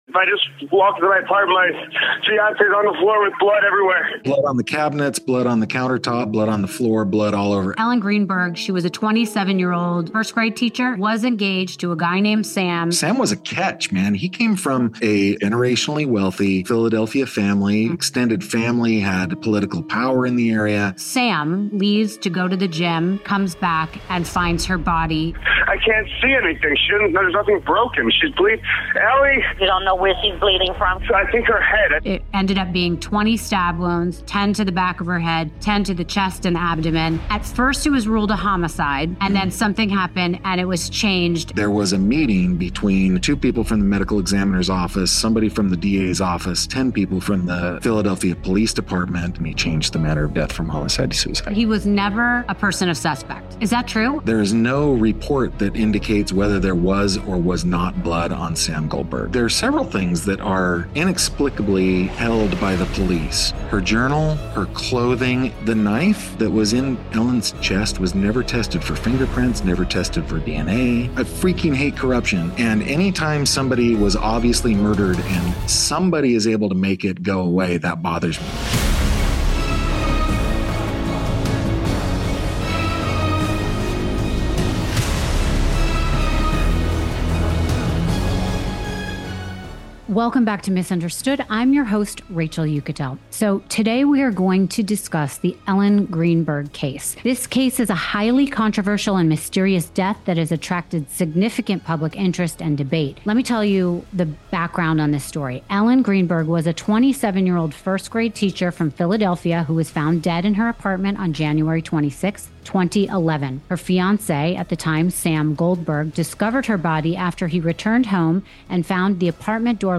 In this powerful conversation